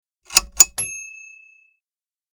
Lamp Switch (being turned on) Sound Effect
A lamp being turned on. This sound is a turn switch but sounds very similar to a lamp with a pull switch.
LampSwitch.mp3